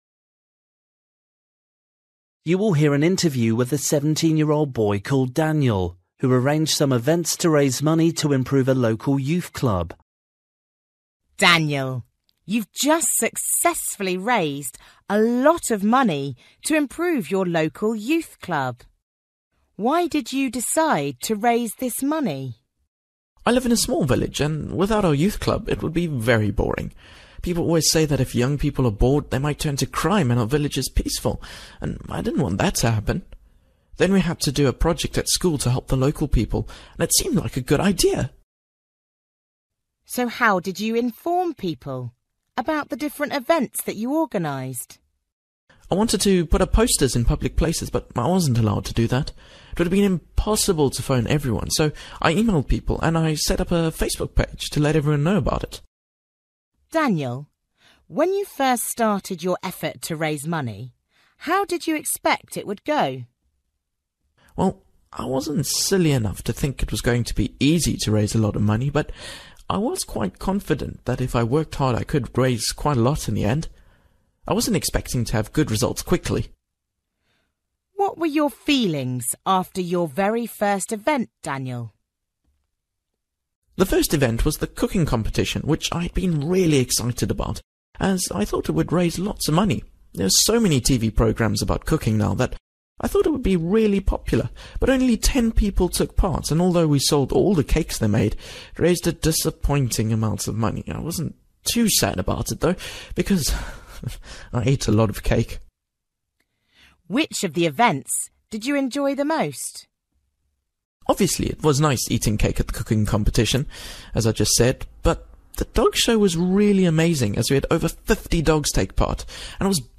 Bài tập trắc nghiệm luyện nghe tiếng Anh trình độ trung cấp – Nghe một cuộc trò chuyện dài phần 25